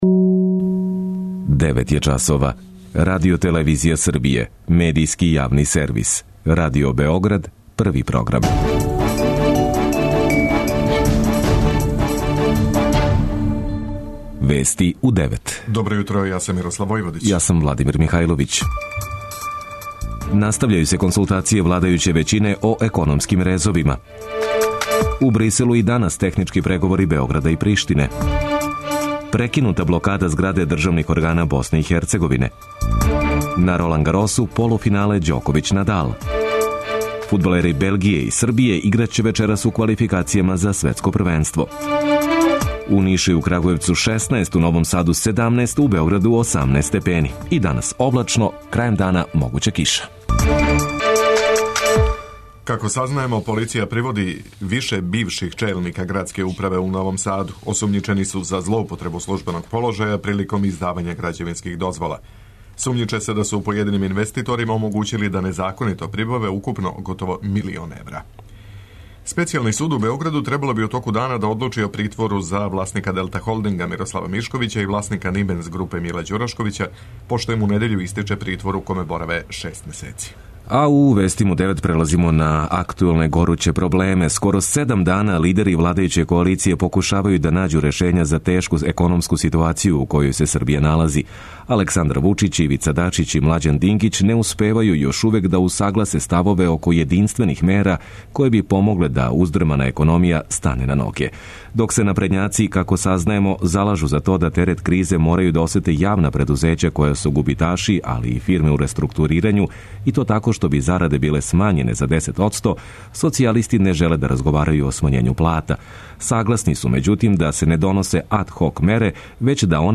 преузми : 10.21 MB Вести у 9 Autor: разни аутори Преглед најважнијиx информација из земље из света.